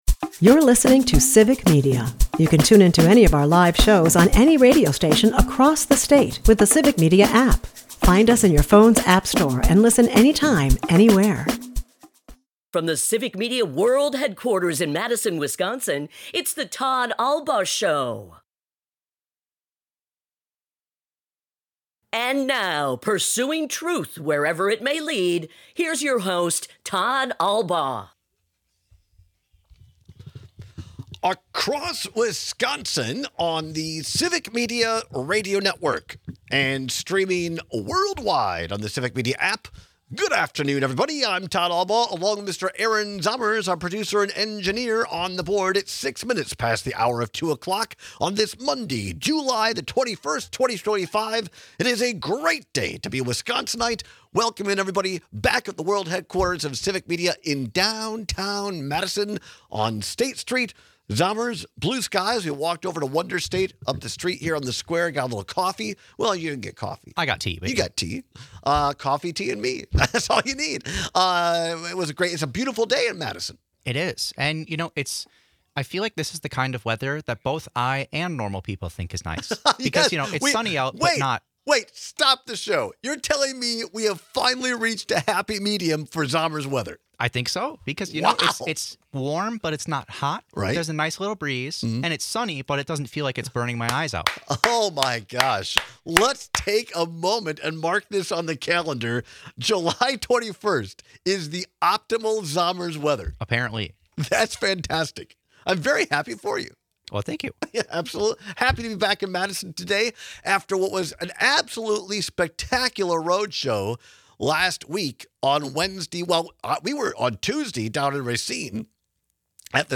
a part of the Civic Media radio network and airs live Monday through Friday from 2-4 pm across Wisconsin